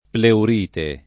pleur&te] s. f. — antiq. pleuritide [pleur&tide] (che prevalse dal ’500 all’800: non ogni dolor di pleura è pleuritide [non 1n’n’i dol1r di pl$ura H ppleur&tide] (Salvini) — pure antiq. pleurisia [pleuri@&a]